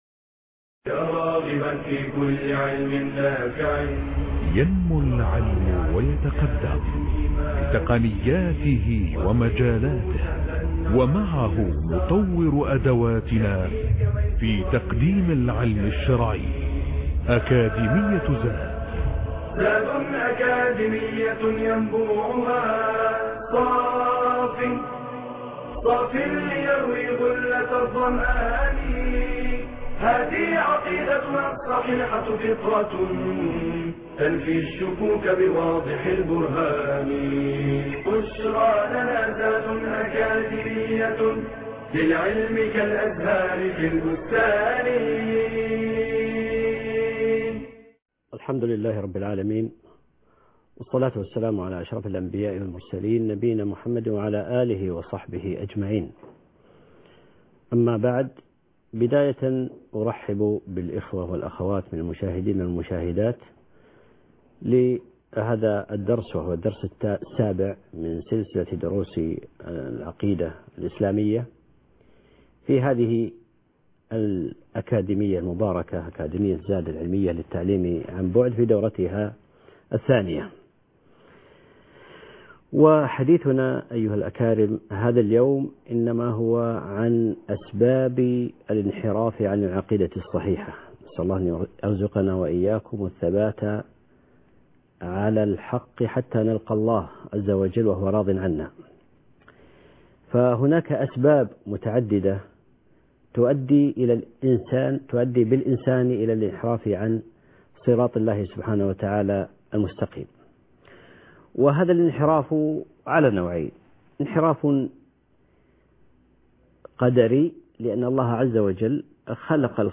المحاضرة السابعه _ الدورة الثانيه - الانحراف عن العقيدة الصحيحه